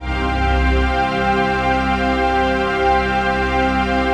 DM PAD2-13.wav